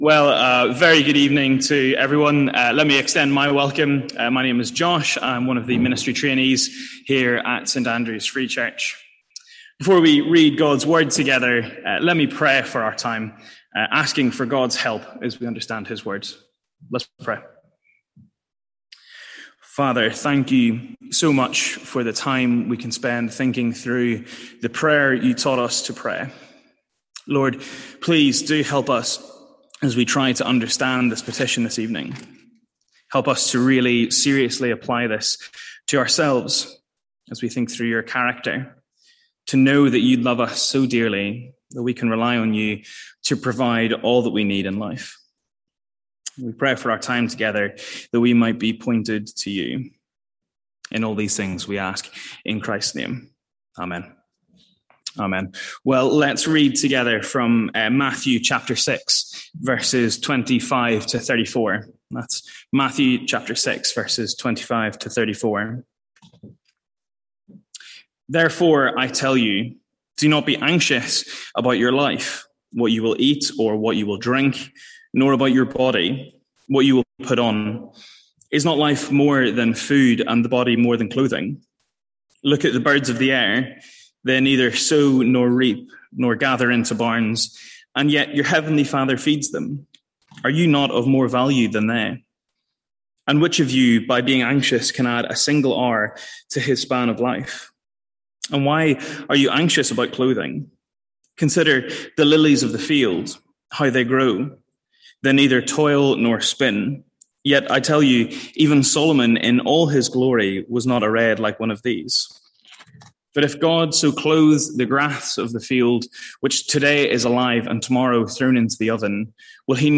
Sermons | St Andrews Free Church